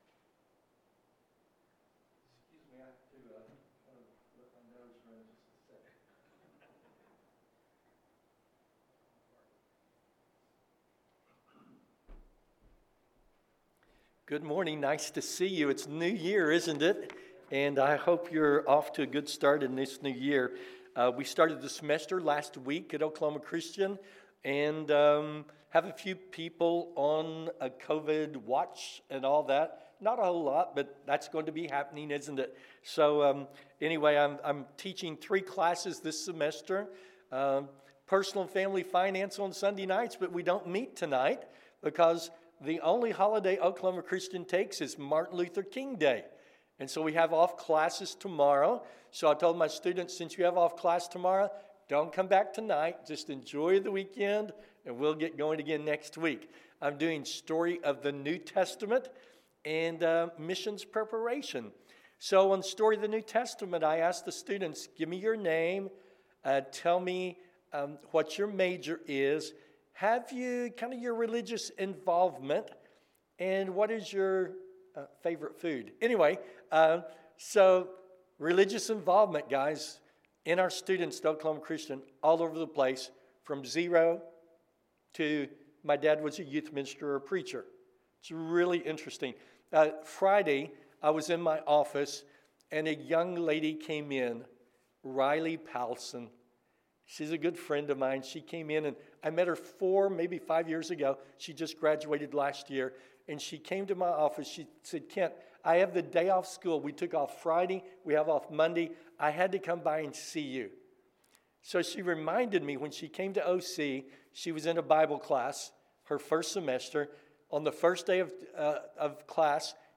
The Preacher & His Family – Sermon